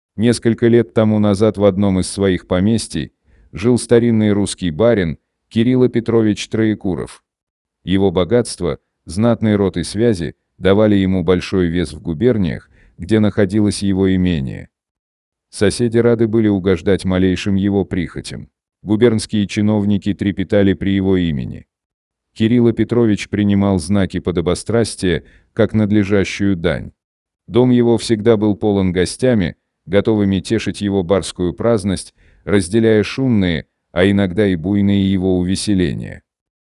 Примеры аудиокниг
Выберите одного из 4-х наших роботизированных дикторов:
Речь робота обладает естественным и выразительным тоном и неизменно высоким качеством